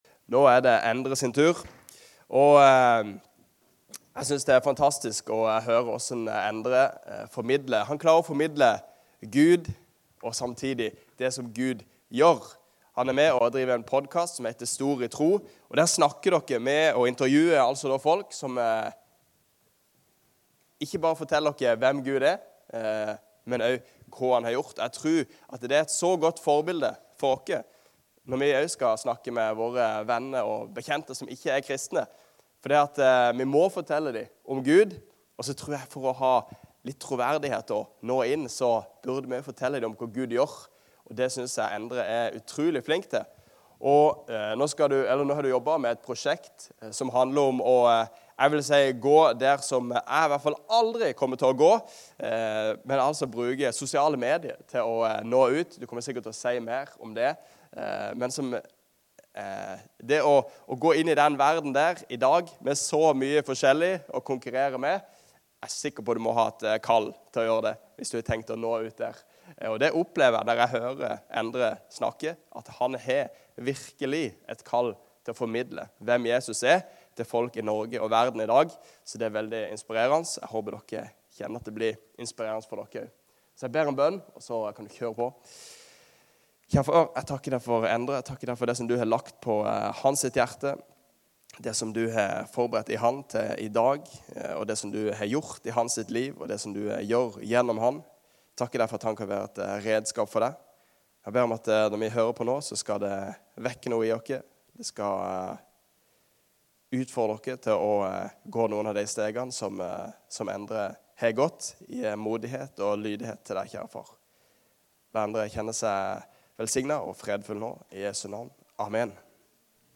12.10.25 – Tale